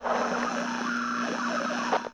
nerfs_psynoise1.ogg